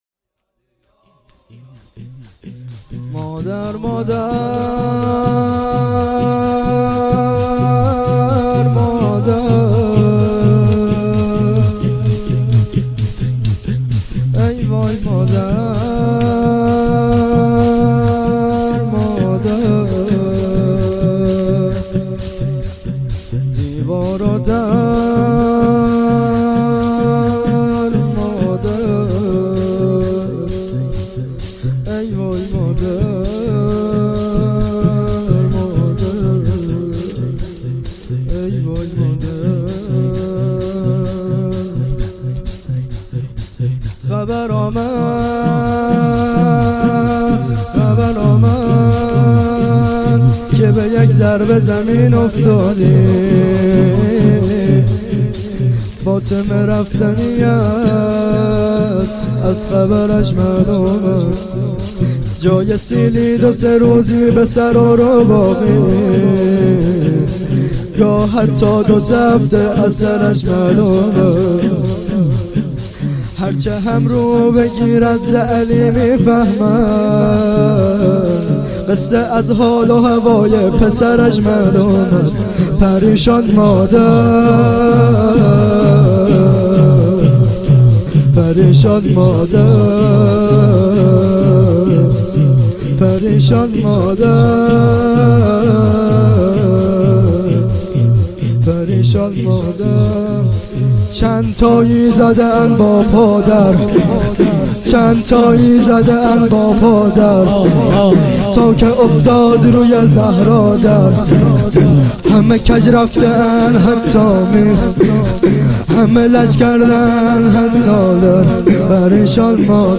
شور روضه ای
شهادت امام حسن عسکری(ع)،97.8.23